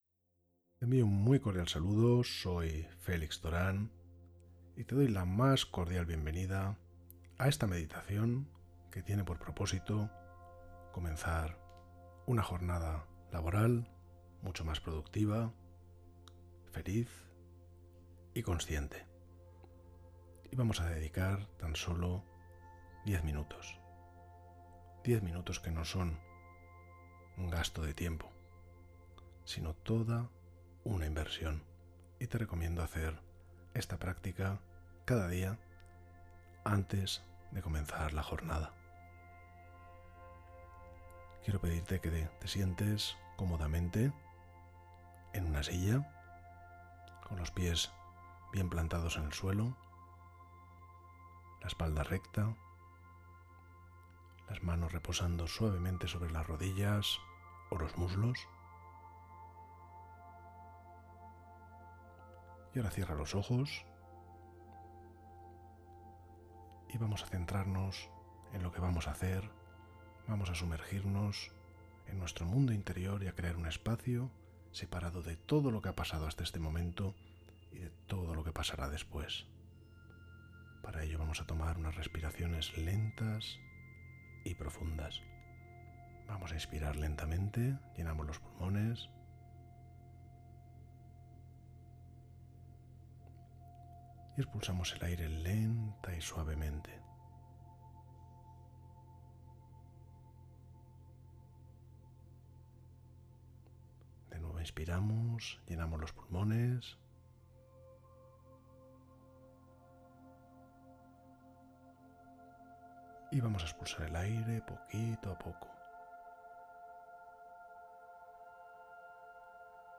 Meditación: 10 minutos para una jornada perfecta